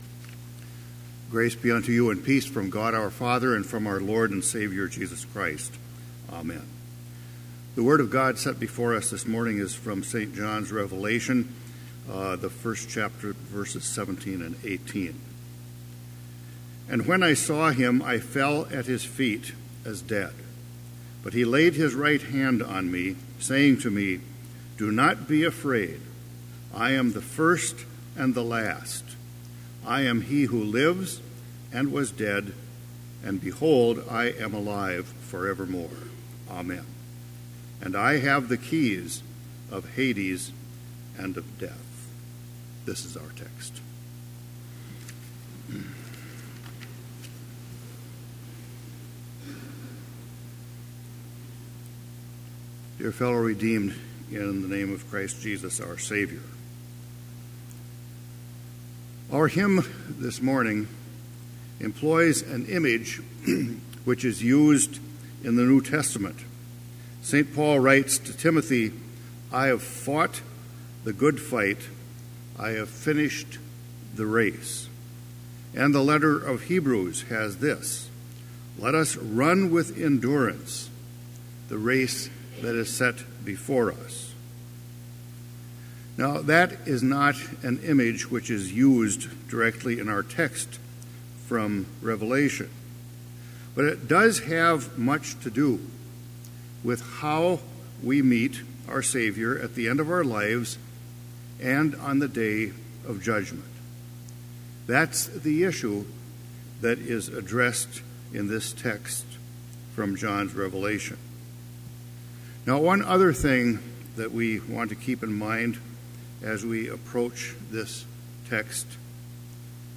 Complete Service
• Prelude
• Hymn 528, vv. 1 & 2, My Course Is Run
This Chapel Service was held in Trinity Chapel at Bethany Lutheran College on Thursday, November 9, 2017, at 10 a.m. Page and hymn numbers are from the Evangelical Lutheran Hymnary.